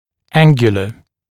[‘æŋgjulə][‘энгйулэ ]угловой, ангулярный